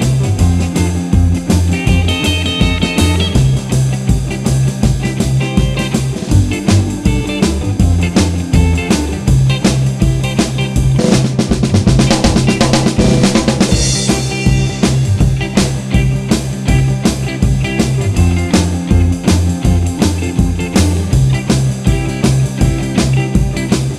No Piano Rock 'n' Roll 2:55 Buy £1.50